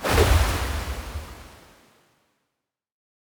water sword Buff 6.wav